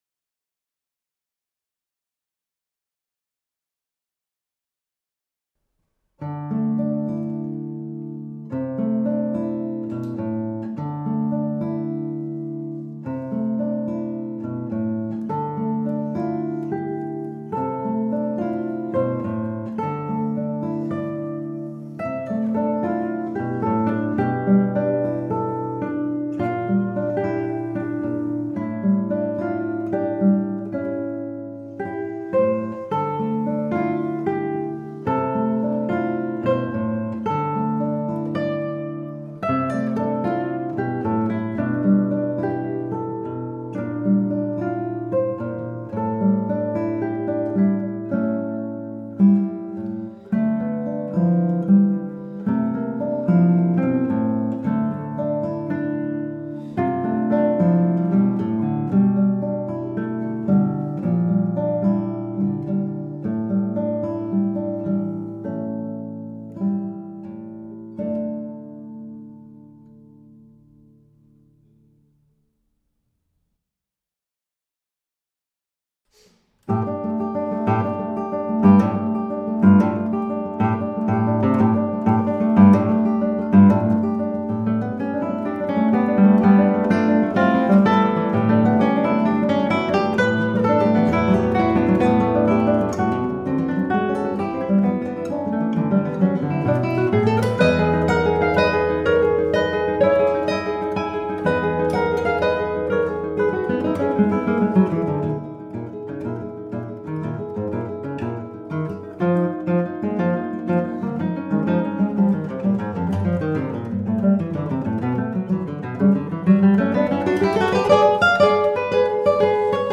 Cinq pièces brèves pour duo de guitare.
Cinq miniatures dont chacune cherche à créer un climat particulier.
La suite commence de manière calme. Sur un arpège obstiné joué par la guitare 2, La guitare 1 déroule une mélodie aérienne qui sera répétée trois fois.
Le scherzo qui va suivre est basé sur le principe du dialogue entre les deux instruments.
La troisième pièce est basée sur un thème joué tout d’abord par la guitare 2 seule.
Le duo intitulé « Mécanique » donne à entendre des batteries de croches ininterrompues qui vont soutenir une mélodie d’esprit léger jouée par la guitare 1.
On y entend de nombreux arpèges ainsi que des changements constants de mesure.